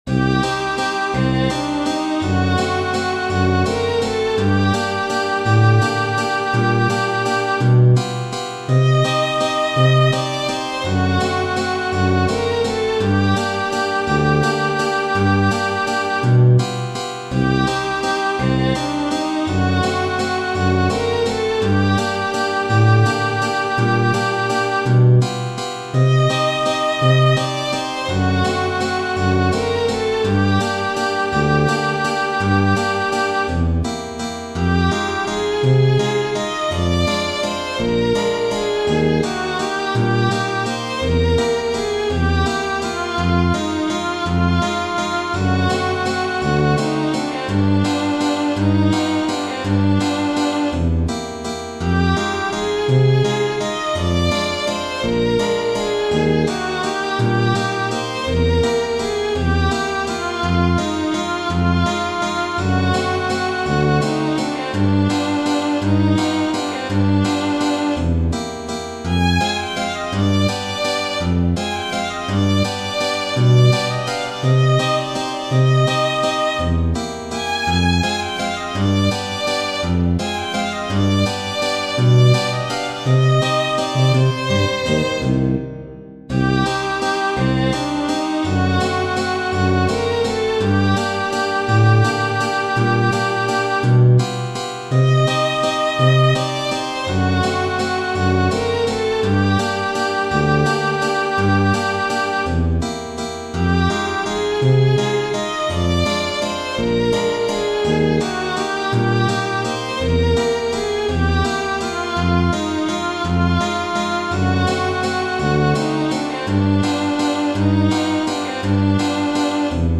Genere: Ballabili
valzer lento